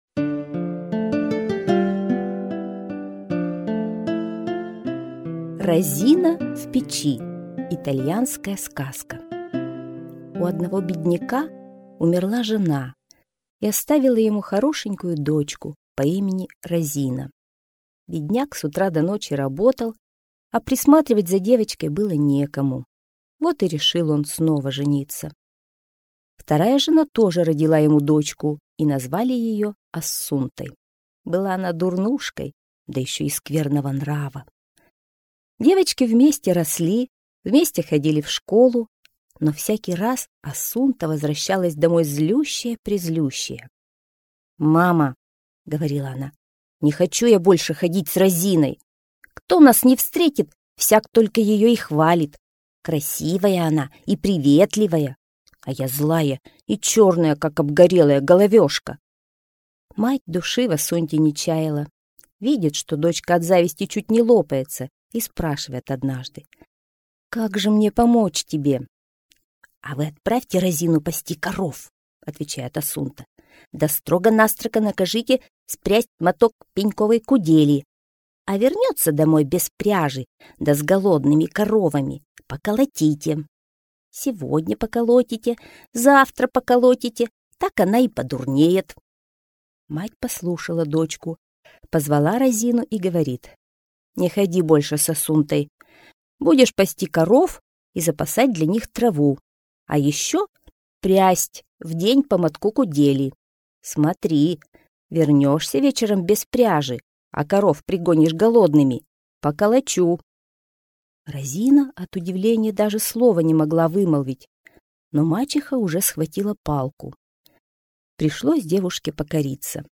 Розина в печи - итальянская аудиосказка - слушать онлайн